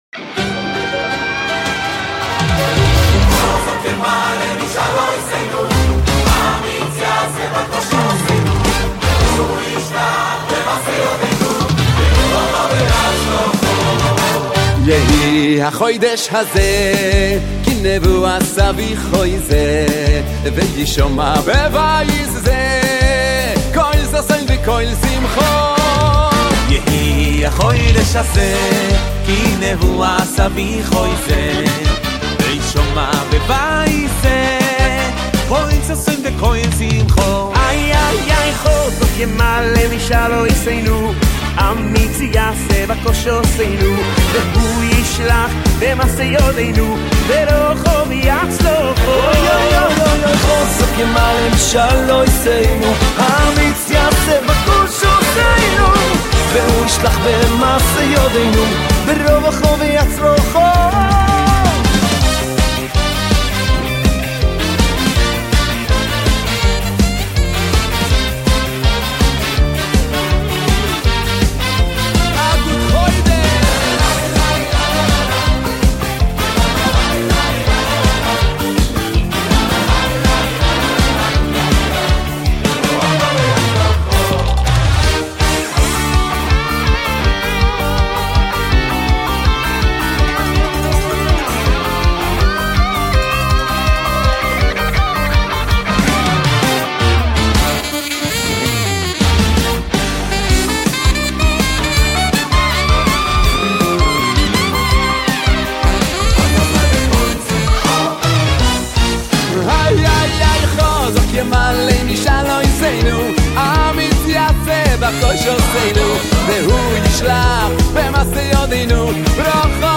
10 דקות של אנרגיה יהודית במיטבה!
לביצועים חדשים ומקפיצים לשירי ראש חודש האהובים.